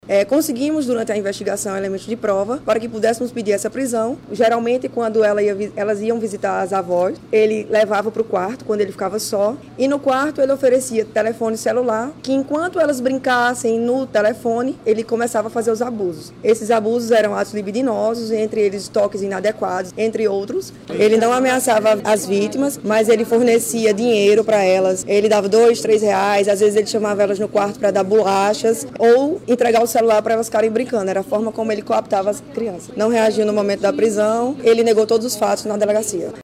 SONORA02_DELEGADA.mp3